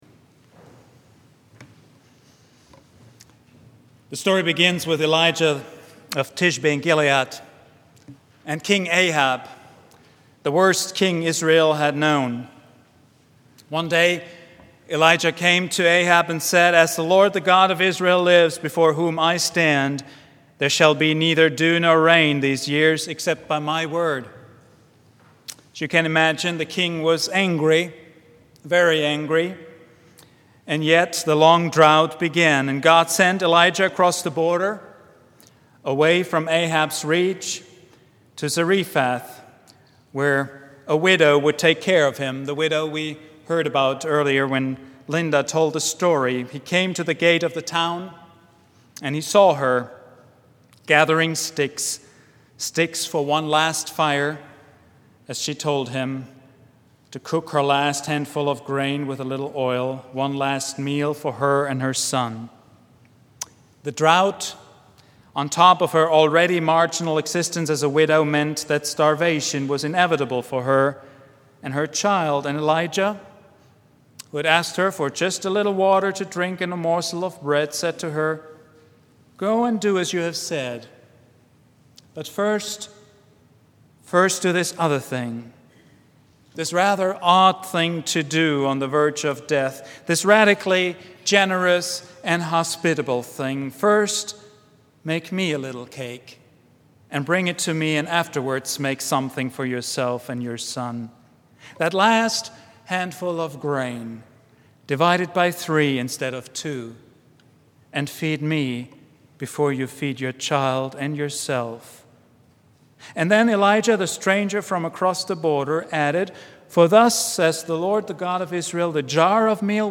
sermon
preached at Vine Street Christian Church on Sunday, June 6, 2010.